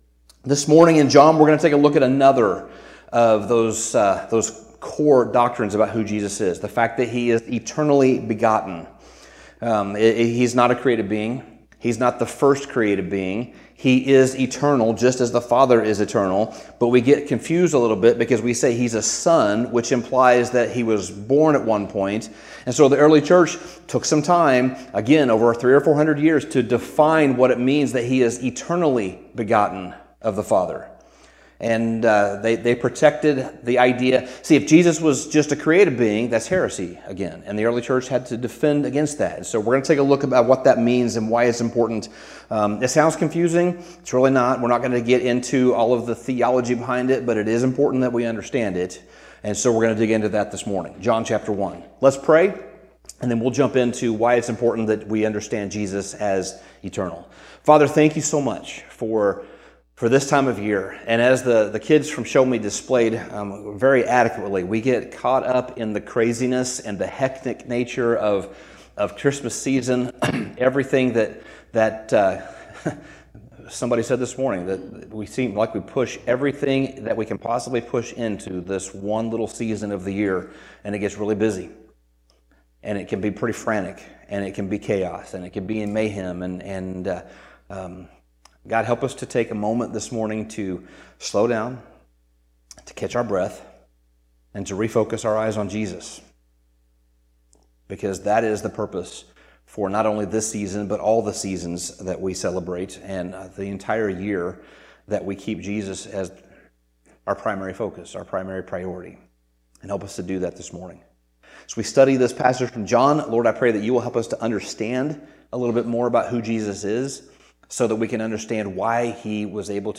Sermon Summary John’s Gospel opens with a profound revelation of Jesus’ identity, beginning with the claim that He existed eternally.